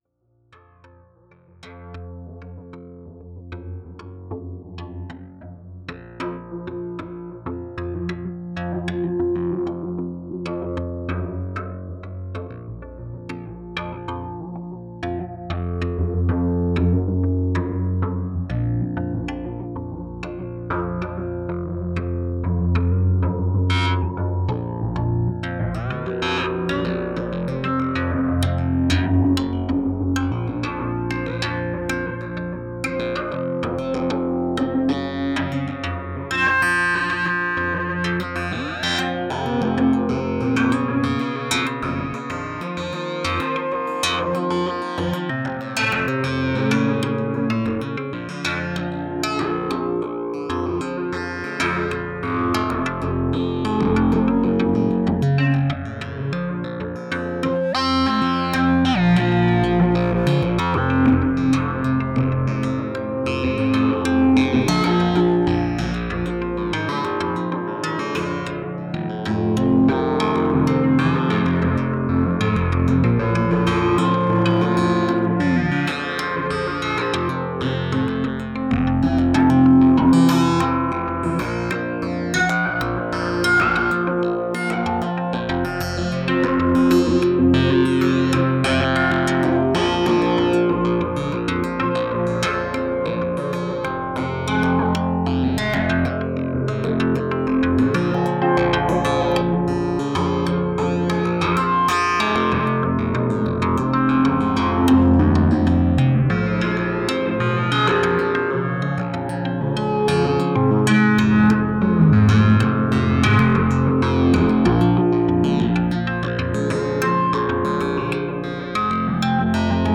Записал Rings, полностью управляемый только с Z8000.
Чуть подкручиваю один 4-шаговый секвенсор на питч Рингса и все. Остальные модуляции - авто. Скорости не меняю, направления не меняю, Гридс не кручу (только в конце).